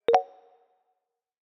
message-received.e2e956cec8e665fdac87.mp3